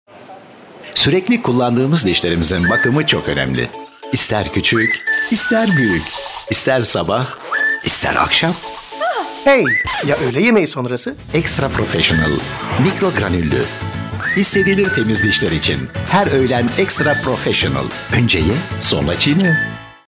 Stimmenprobe
Wrigleys_BrushRituals_20sec-Turk_16zu9-HQ.mp3